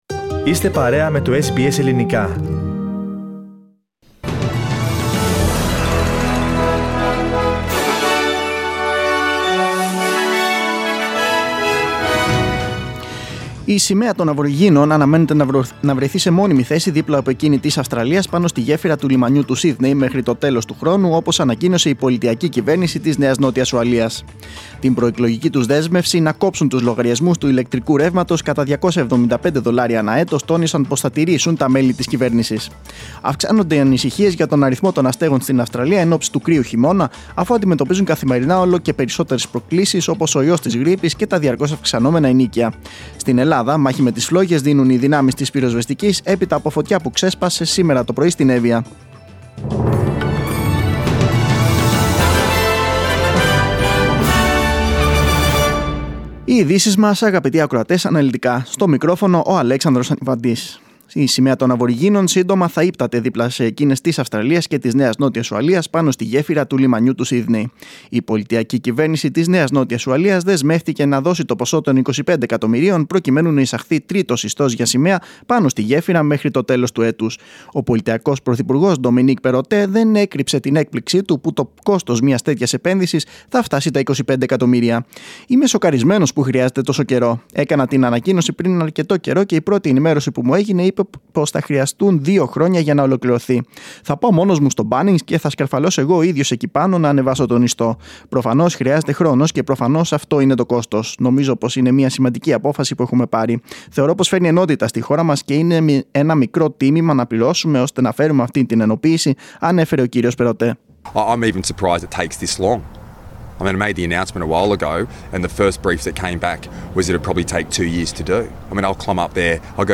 Δελτίο Ειδήσεων 19.6.2022
News in Greek. Source: SBS Radio